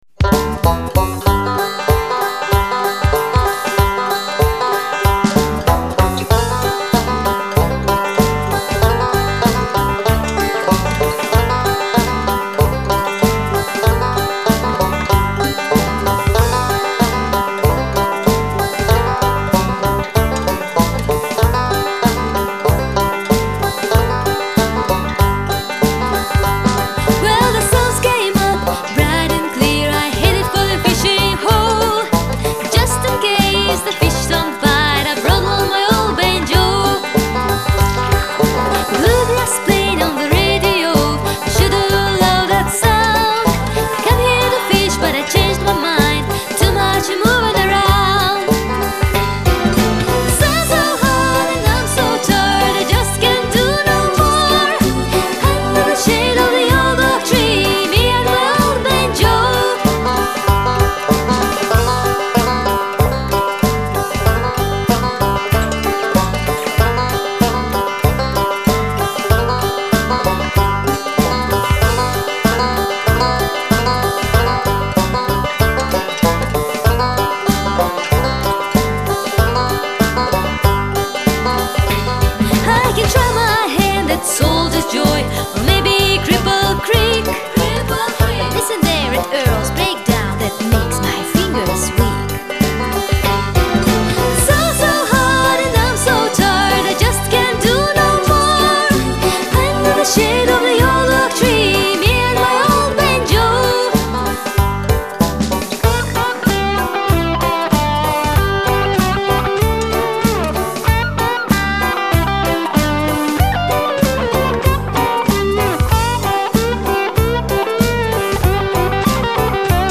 oldbanjo.mp3